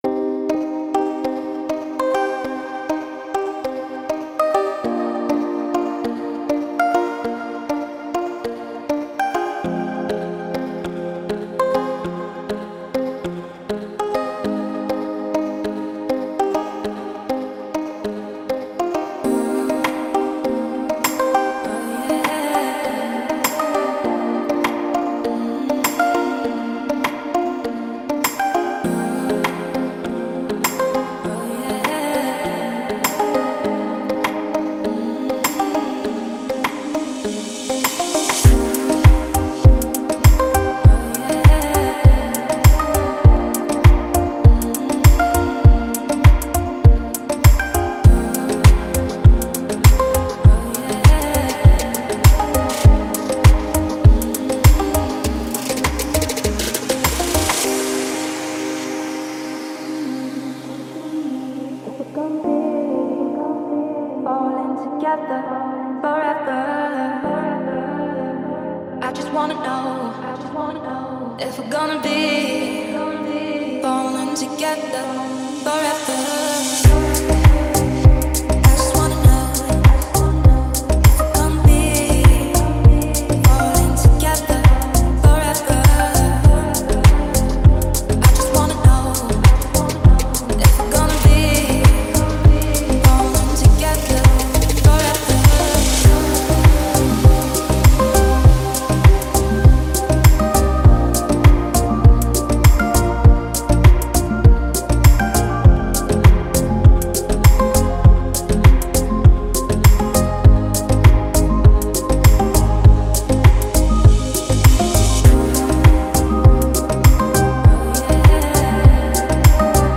это яркий трек в жанре поп